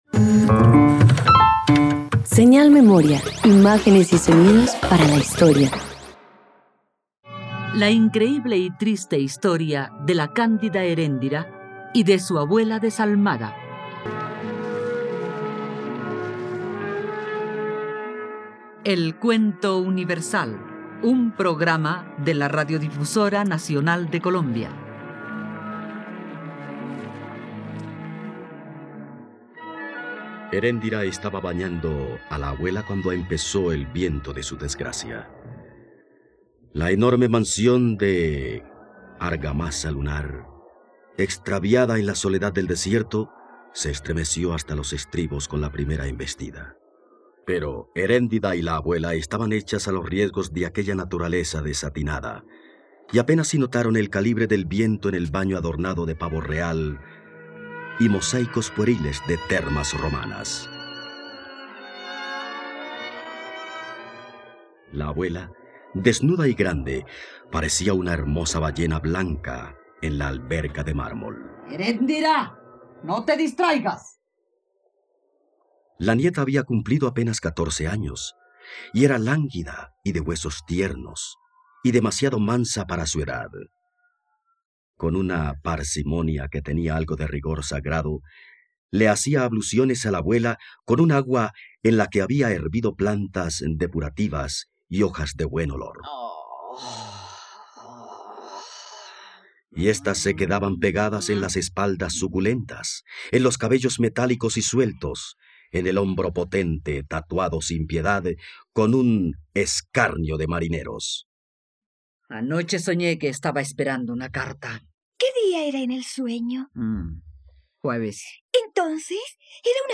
..Radioteatro. Escucha la triste historia de la cándida Eréndira y su abuela desalmada del escritor colombiano Gabriel García Márquez.